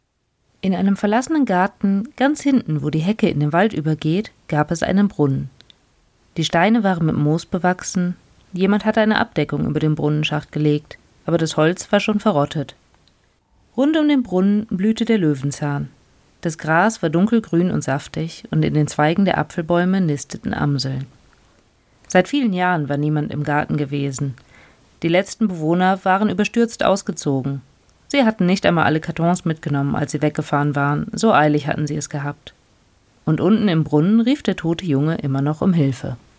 Natürlich gibt es noch eine Menge zu lernen – was das Vorlesen angeht genau so wie in Bezug auf die Technik-Technik.
PS: Falls ihr im Hintergrund ein gleichmäßiges Summen wahrnehmt – nein, das ist kein ultracooler Rausch-Effekt, sondern der schnurrende Kater auf dem Sofa hinter mir.